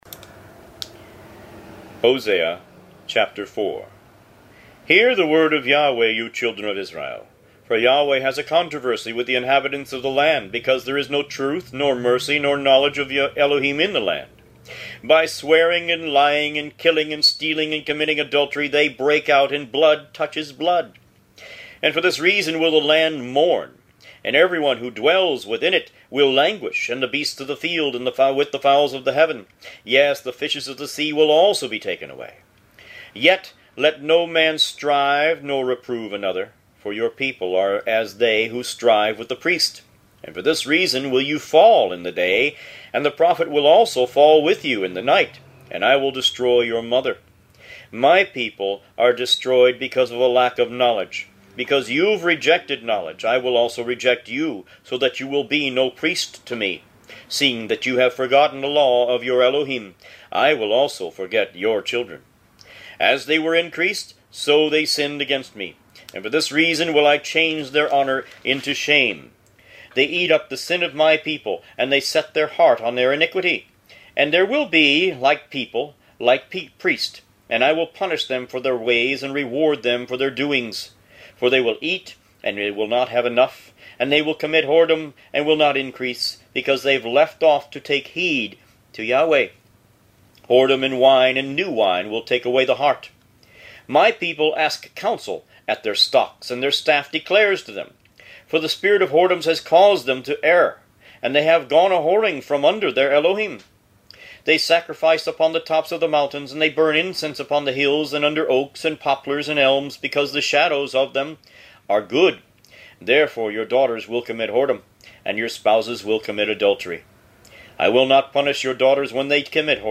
Root > BOOKS > Biblical (Books) > Audio Bibles > Tanakh - Jewish Bible - Audiobook > 28 Hosea